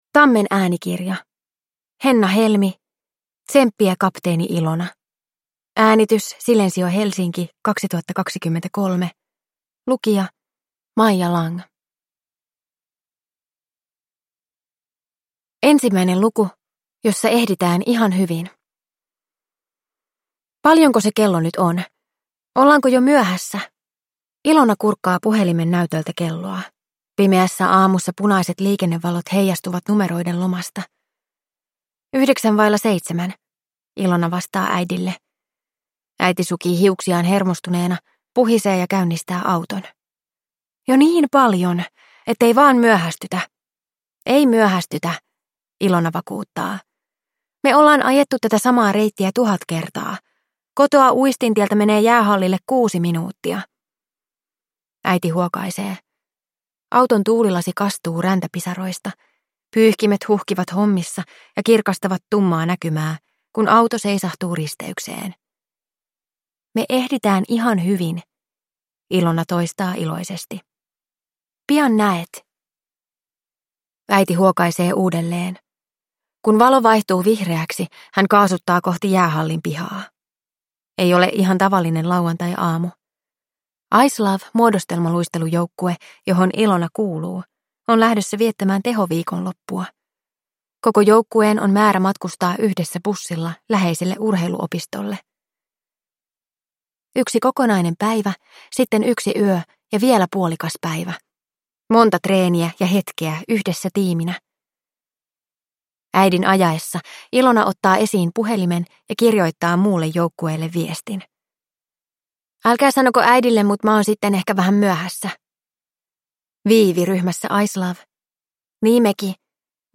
Tsemppiä, kapteeni Ilona! – Ljudbok